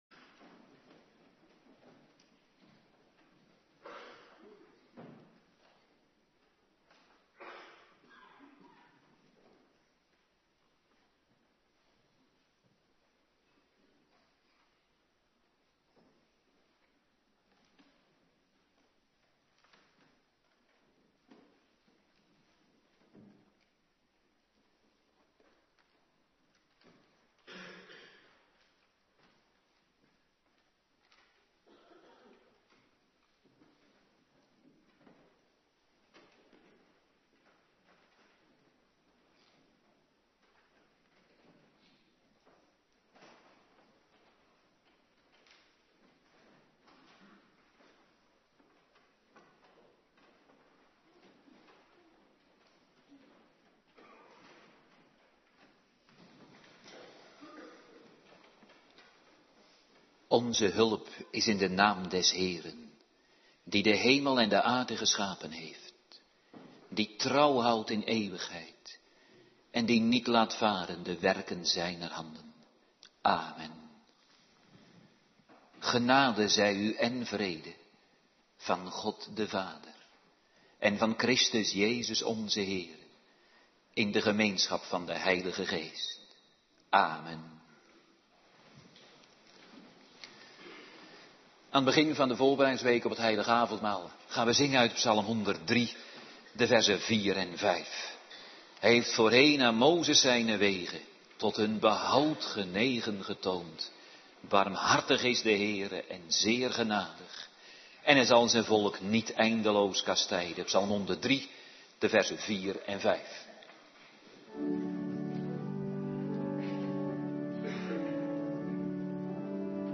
Morgendienst Voorbereiding Heilig Avondmaal
09:30 t/m 11:00 Locatie: Hervormde Gemeente Waarder Agenda: Kerkdiensten Extra info: Jo�l 2:13 Terugluisteren Joel 2:1-17